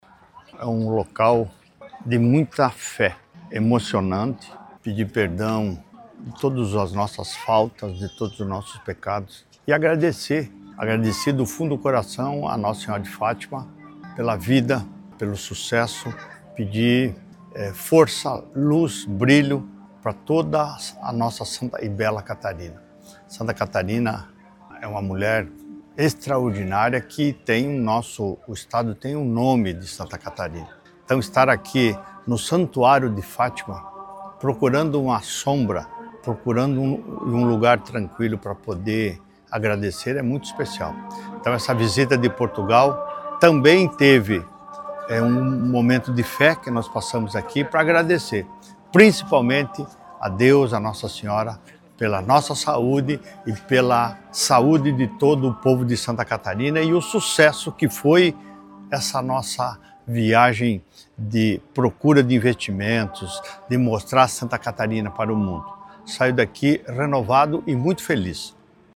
SECOM-Sonora-governador-visita-Fatima-1.mp3